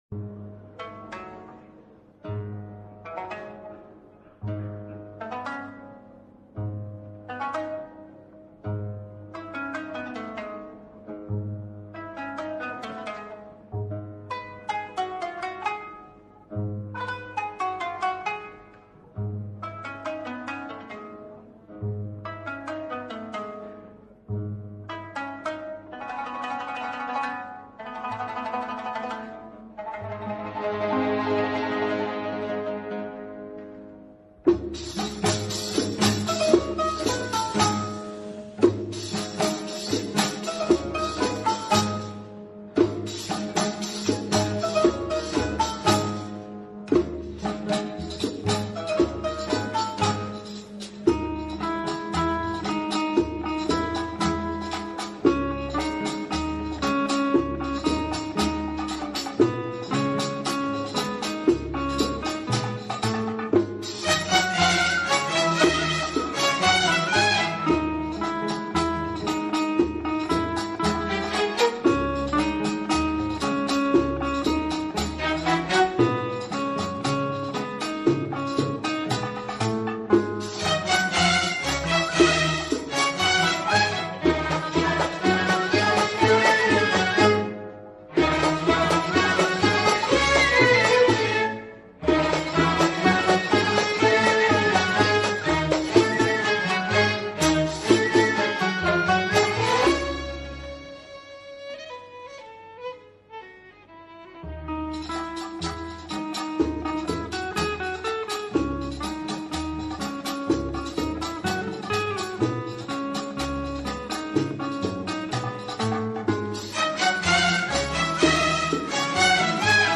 ارکستر بزرگ
بداهه‌نوازی‌های طولانی ویولن و عود